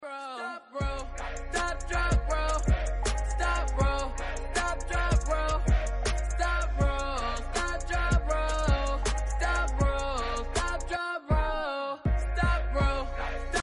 Genres: Hip hop, trap, cloud rap